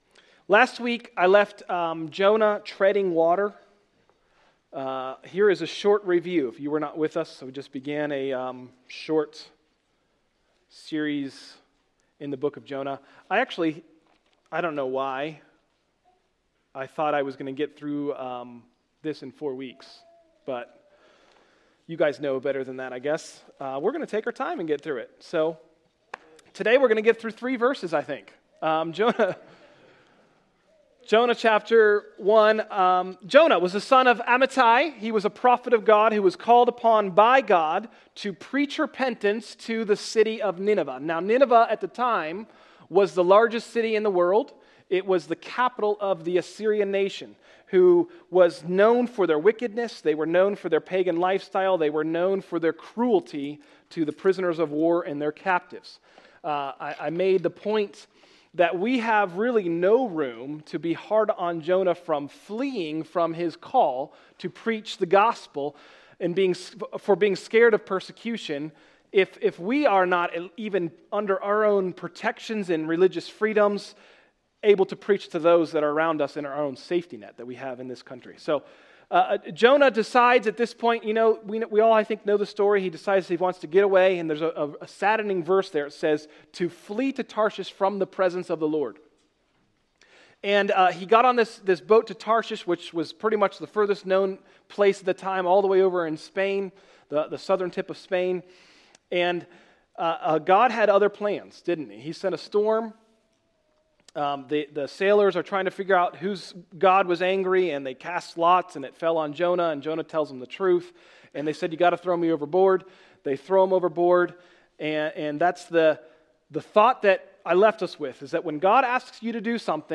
Sermons – Tried Stone Christian Center